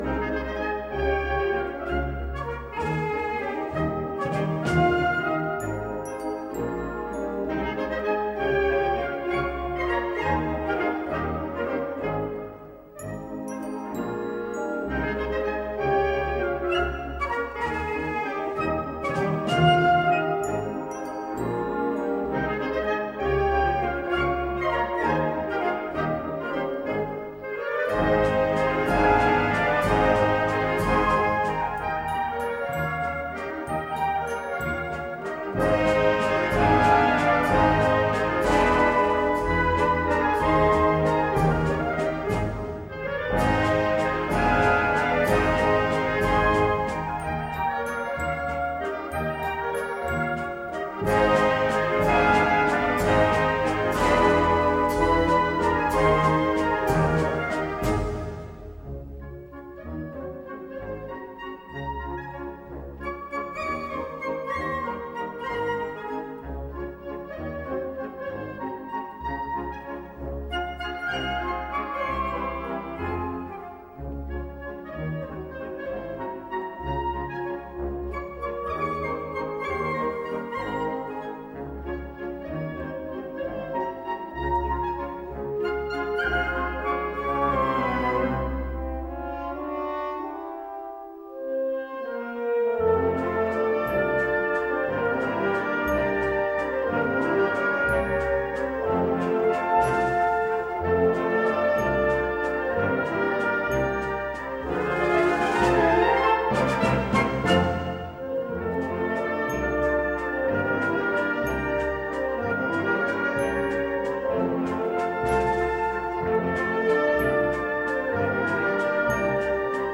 Répertoire pour Harmonie/fanfare